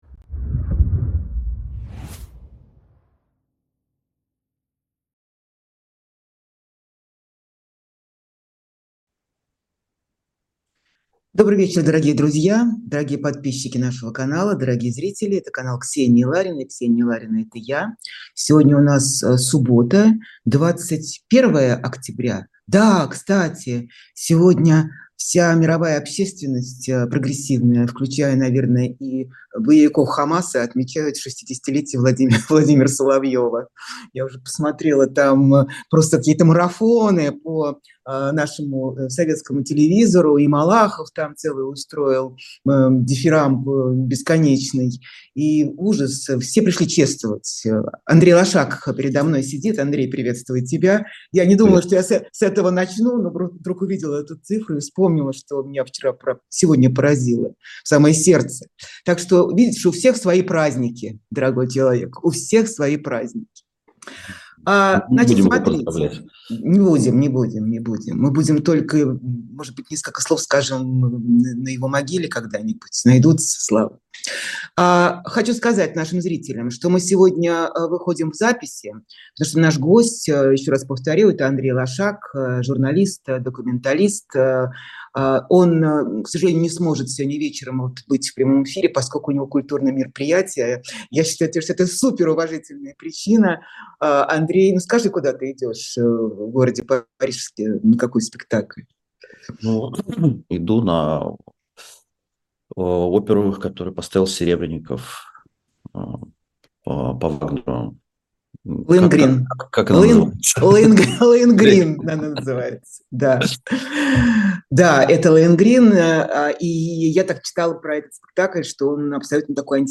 Андрей Лошак документалист, журналист